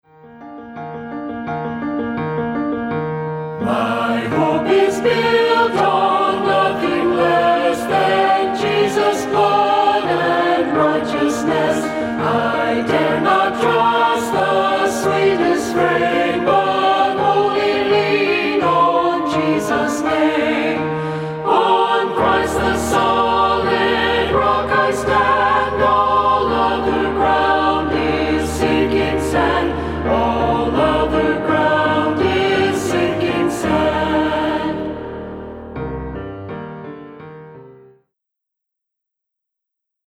STUDIO: Broadway Recording Studio, Broken Bow, OK
CONGREGATIONAL ANTHEM DEMOS